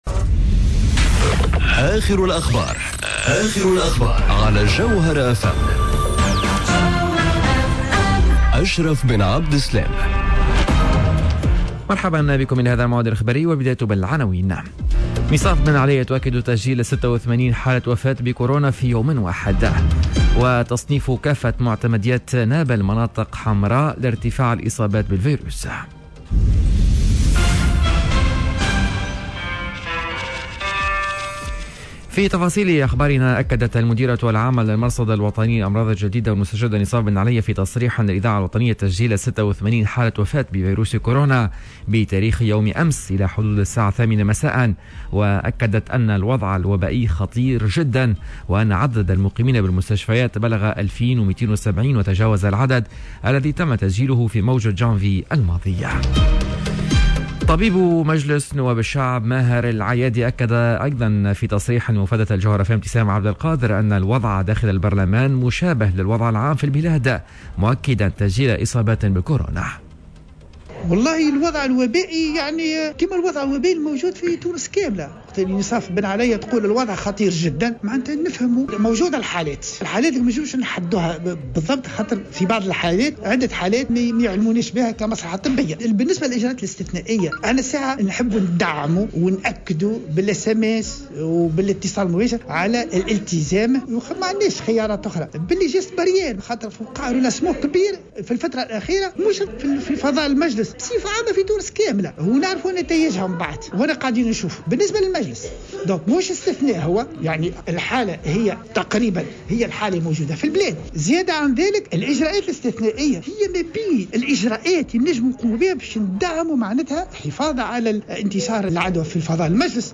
نشرة أخبار منتصف النهار ليوم الإربعاء 14 أفريل 2021